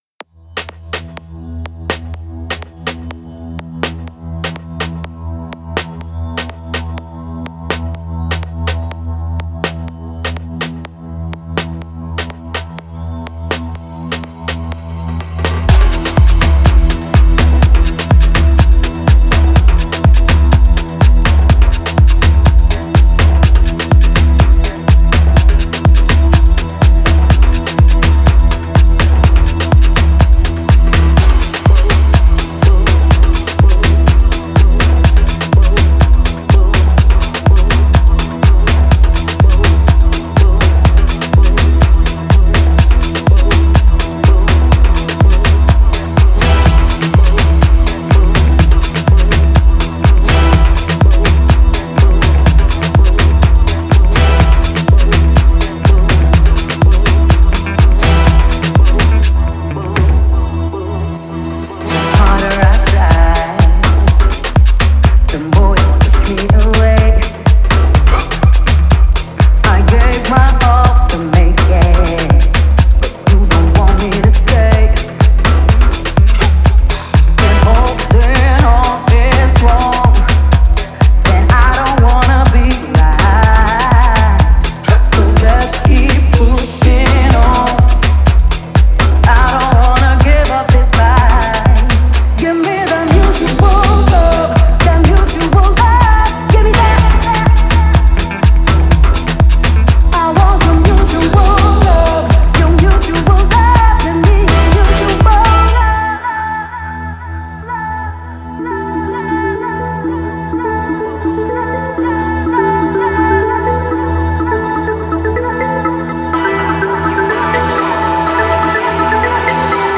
Afro music